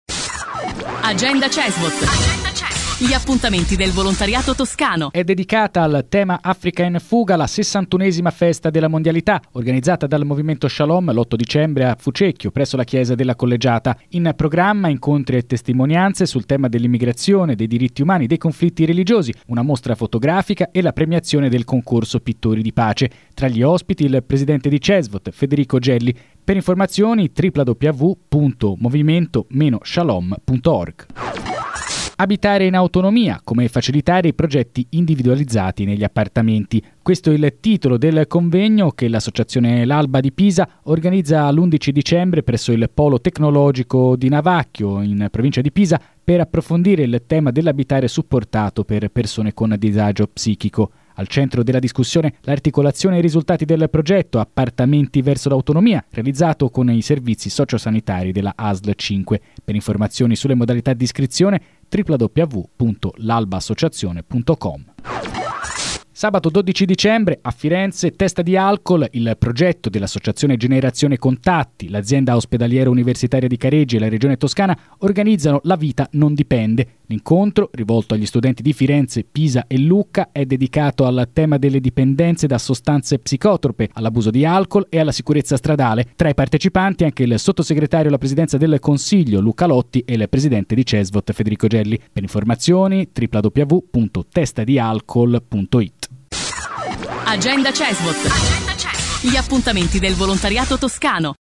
Anche questa settimana sono tante le inziative del volontariato che sentirete sulle principali radio toscane. Tre, infatti, le rubriche promosse da Cesvot, in collaborazione con Controradio, Contatto Radio, Radio Toscana, Novaradio: Associazioni in radio, Volontariato in onda e Agenda Cesvot.